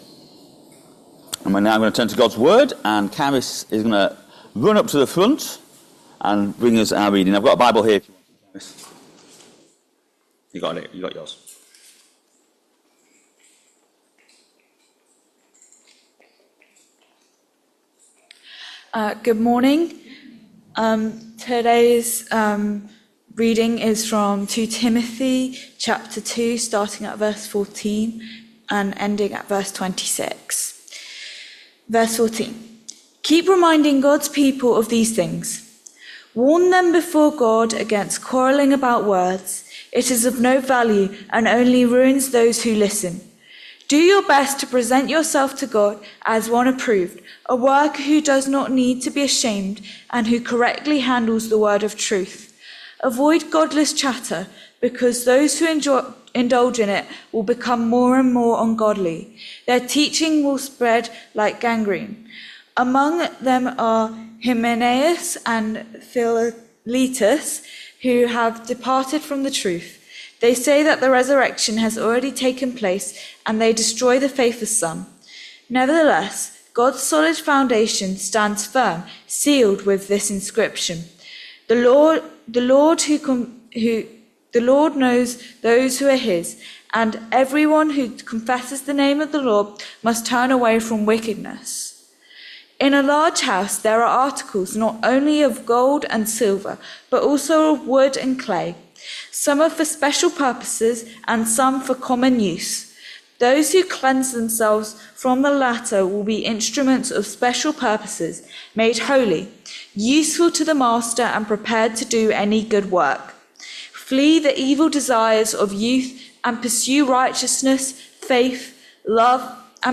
2 Timothy 2vv14-26 Service Type: Sunday Morning Service Topics